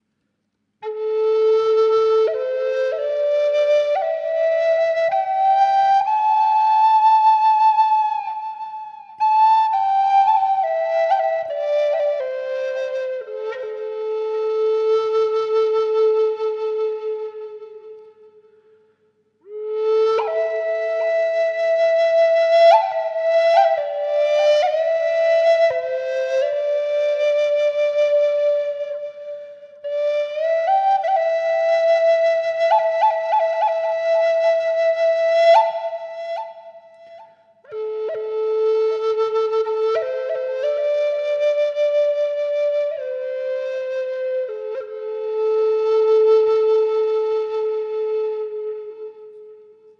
A4 sävellajin Natiivihuilu
Natiiviamerikkalaishuilu A4 sävellajissa. Korkeahko ja lempeä sointitaajuus. Viritetty pentatoniseen molli sävelasteikkoon.
Huilu on valmistettu koivusta.
• Vire: Pentatoninen molli (440 hz)
Ääninäyte kaiku/reverb efektillä:
A4_440_pentatoninenmolli_FX.mp3